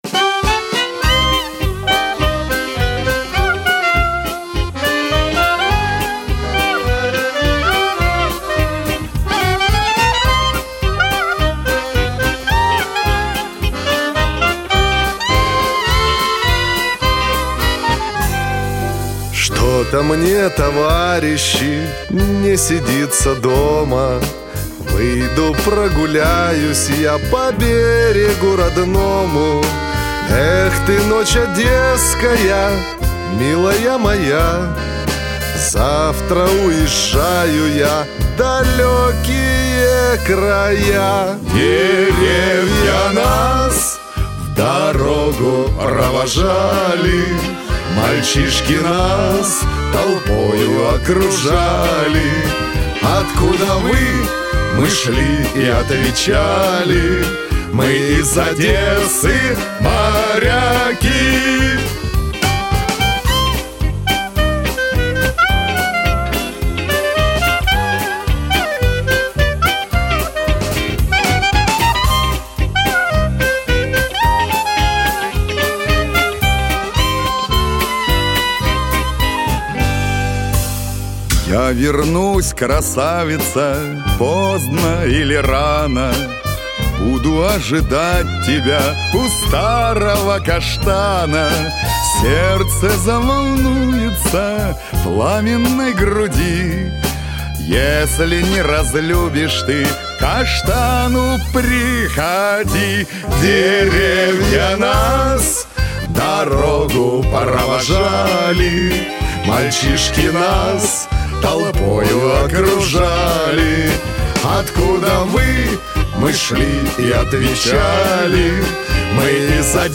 клезмер-бэнд